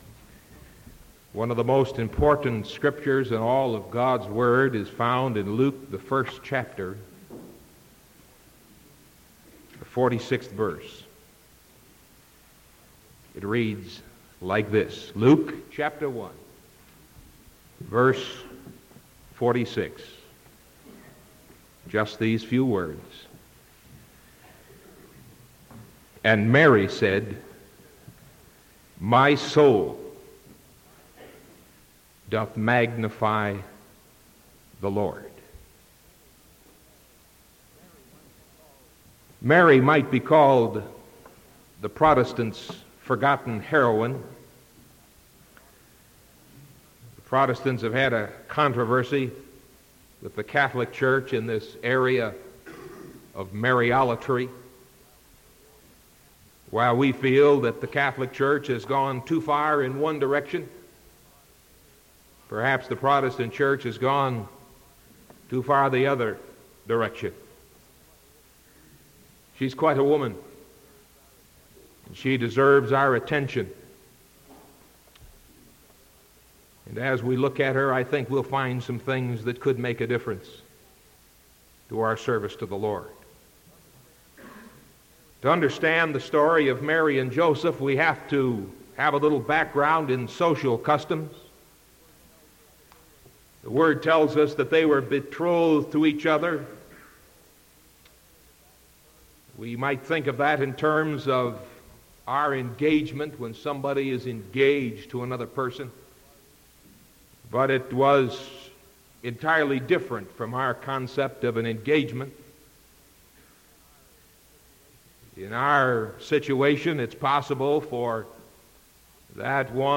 Sermon February 9th 1975 PM